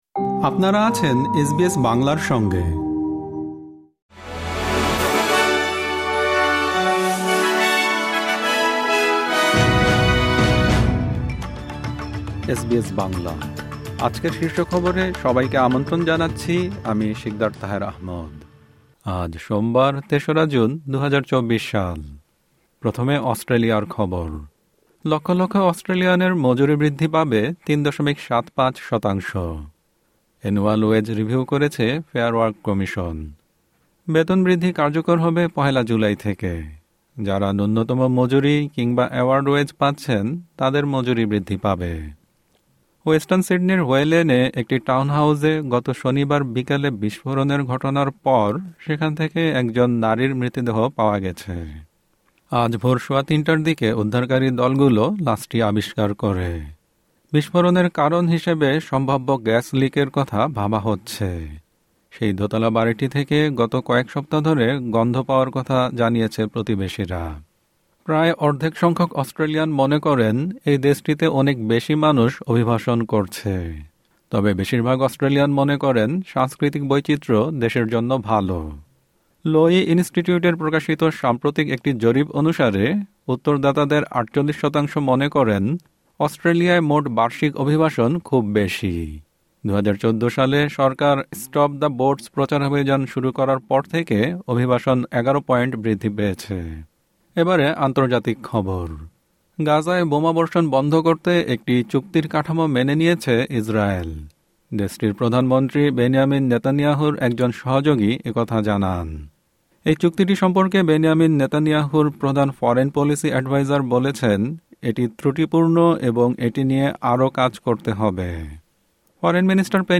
এসবিএস বাংলা শীর্ষ খবর: ৩ জুন, ২০২৪